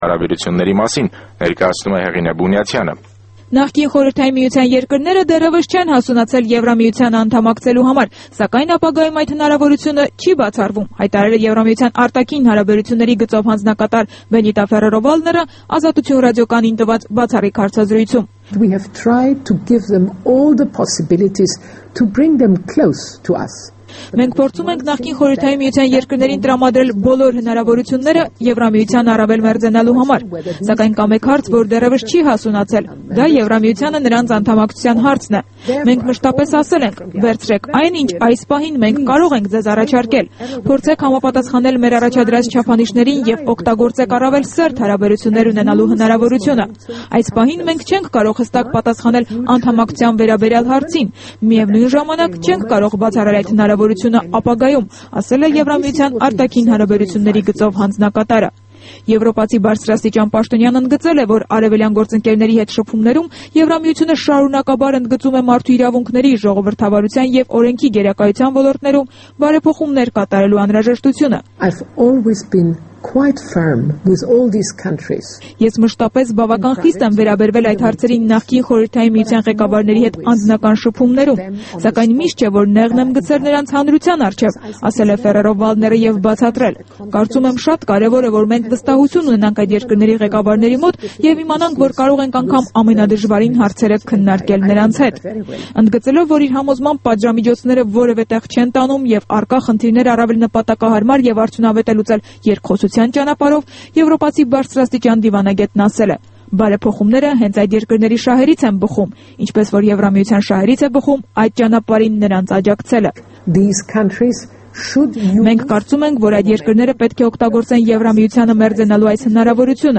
Բենիտա Ֆերերո-Վալդների հարցազրույցը